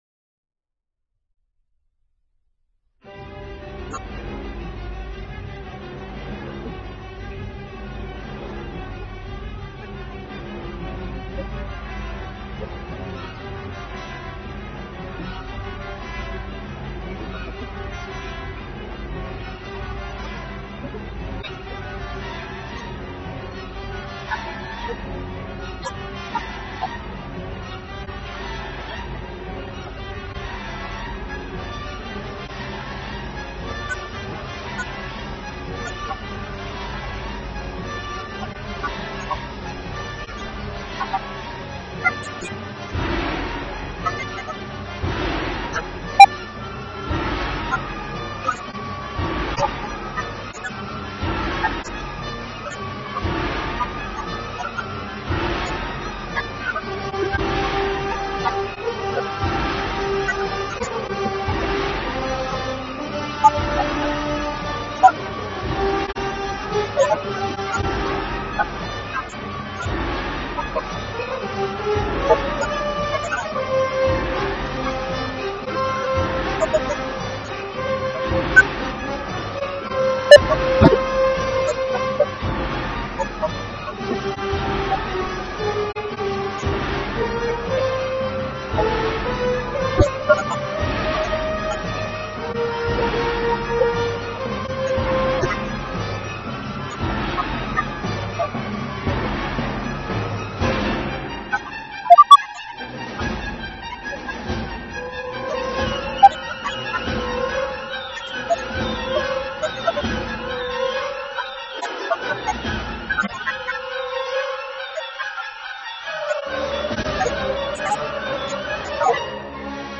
The pounding, onomatopoeic machine-rhytms